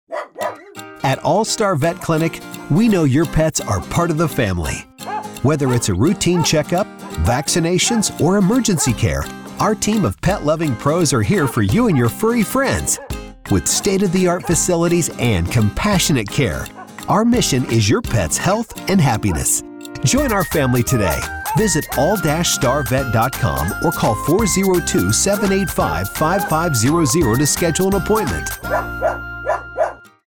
ad samples.
Vet-Commercial.mp3